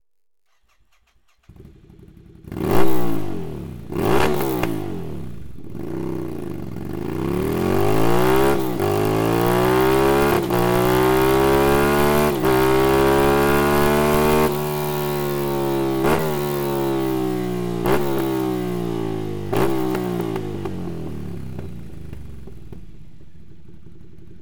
Dank eines Verbindungsrohrs aus reinstem Titan ist diese wunderschön geformte Abgasanlage auch ohne Vor-Schalldämpfer perfekt auf den Charakter des Motors abgestimmt und verleiht diesem einen noch stärkeren Sound.
Spezielle abnehmbare Geräuschdämpfungseinlagen wurden entwickelt, um zwei verschiedene Soundoptionen für das Bike zu bieten.
Sound Akrapovic Slip-On